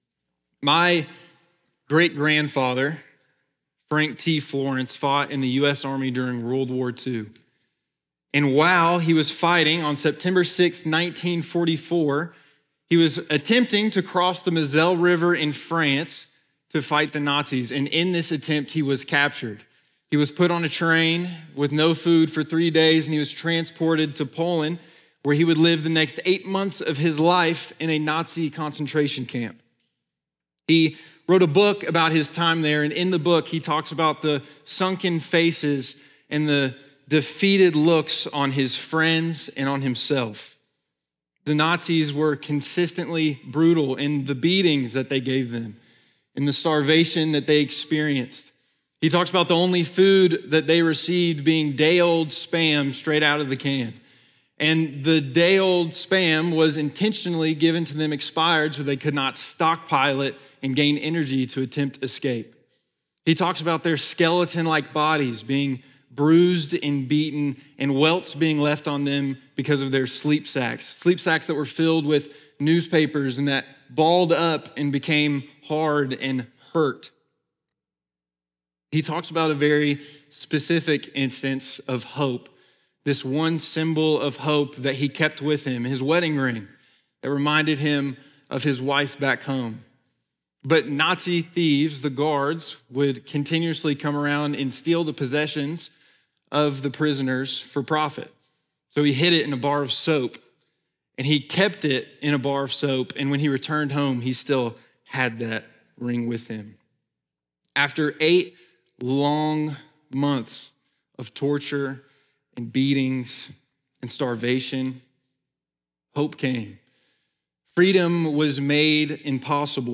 Ashland Sermon (Romans 7: 21-25) - Ashland Church